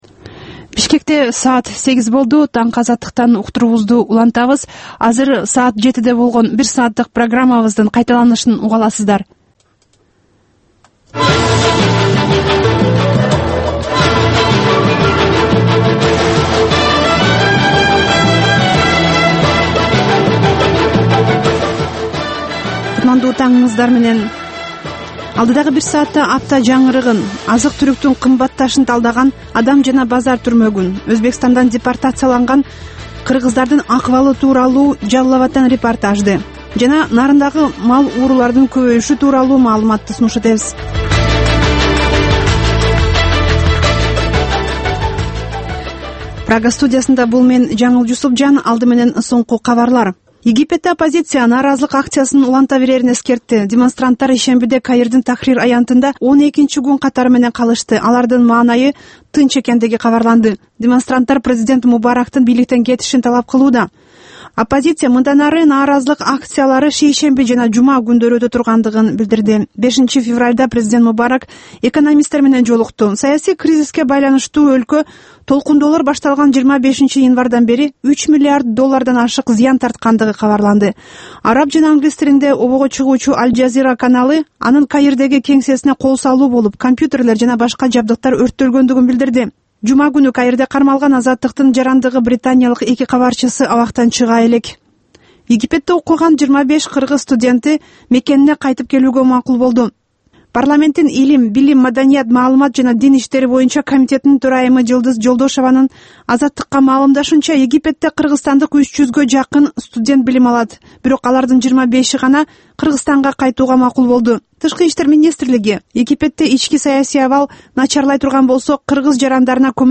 Таңкы 8деги кабарлар